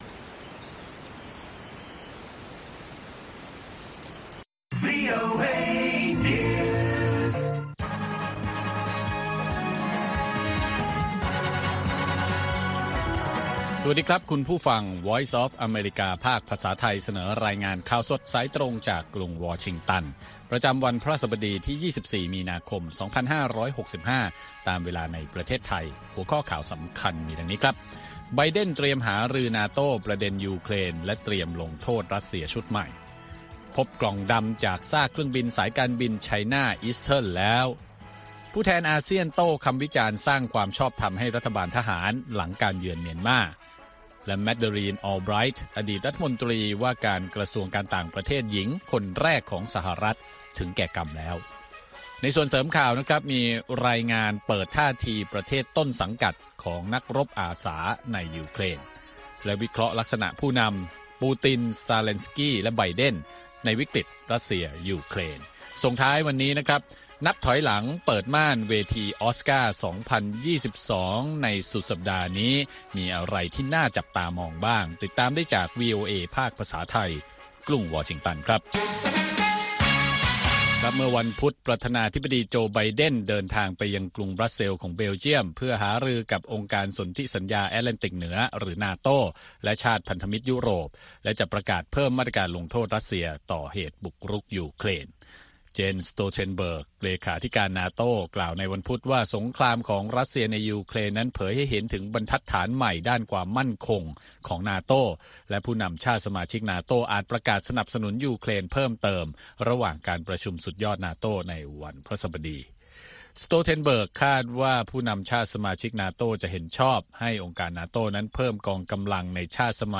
ข่าวสดสายตรงจากวีโอเอ ภาคภาษาไทย 6:30 – 7:00 น. ประจำวันพฤหัสบดีที่ 24 มีนาคม 2565 ตามเวลาในประเทศไทย